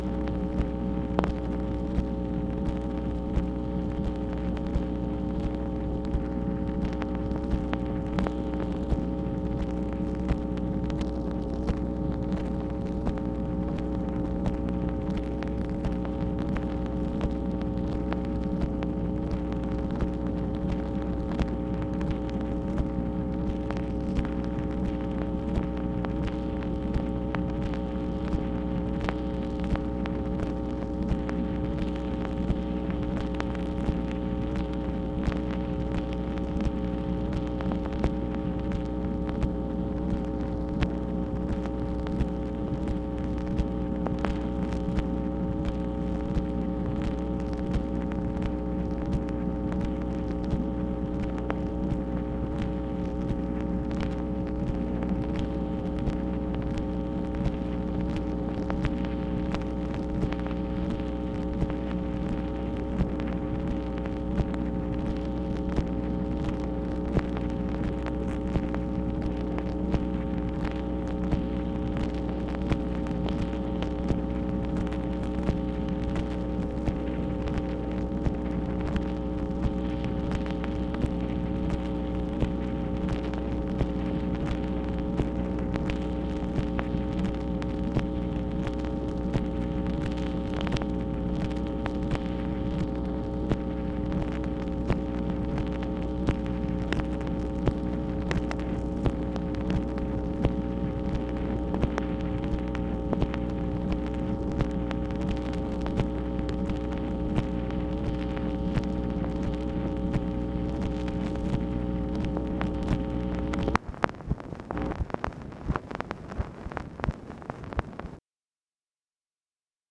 MACHINE NOISE, December 10, 1966
Secret White House Tapes | Lyndon B. Johnson Presidency